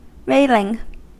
Ääntäminen
Ääntäminen US : IPA : [ˈɹeɪ.lɪŋ] Haettu sana löytyi näillä lähdekielillä: englanti Käännöksiä ei löytynyt valitulle kohdekielelle.